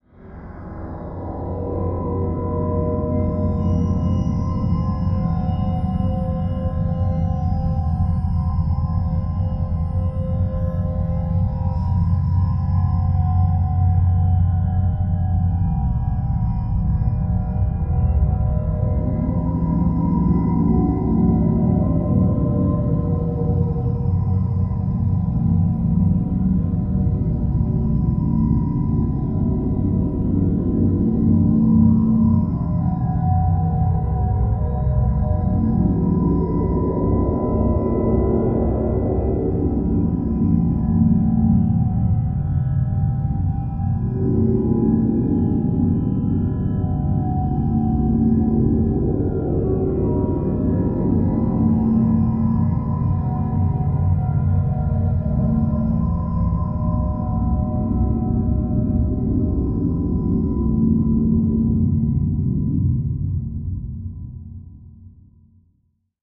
Discovery dark room tone with twisted ringing and rising/falling motion